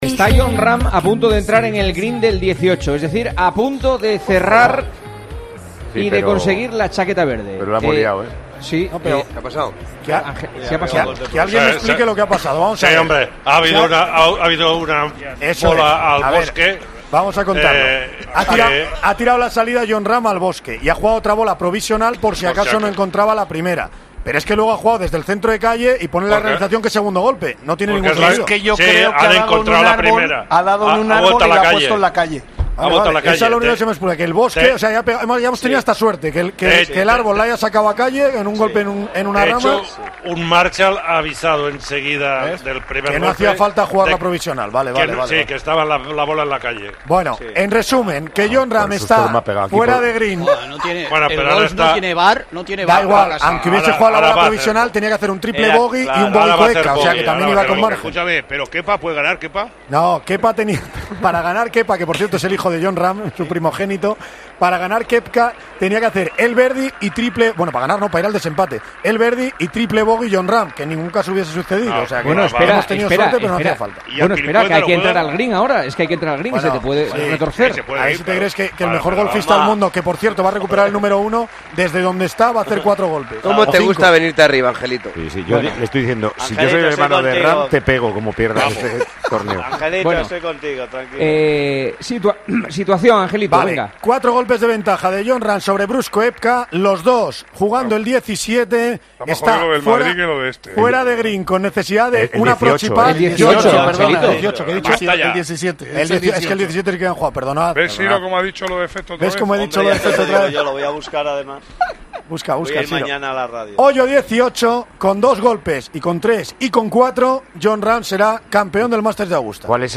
Así vivimos el último hoyo del Masters de Augusta, en Tiempo de Juego